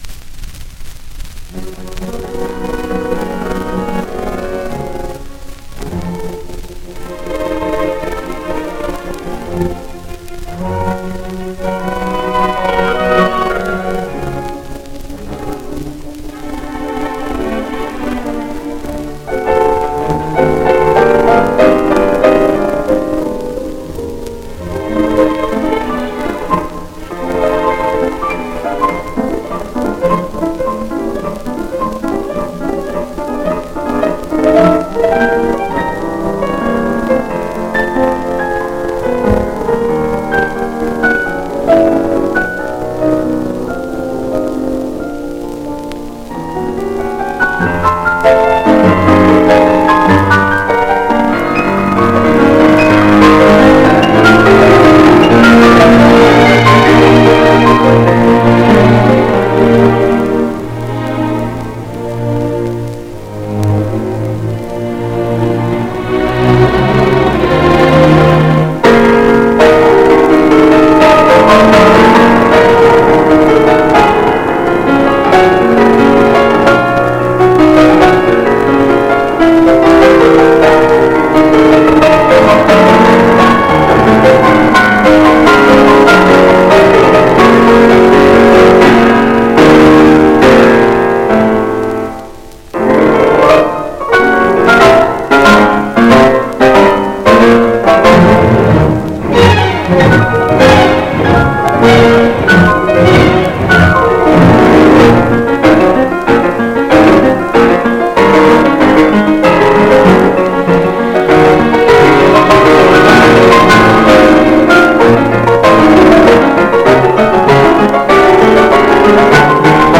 Concerto No. 2 in B flat major, Op. 83. 1st movement (conclusion)